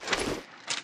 equip_iron2.ogg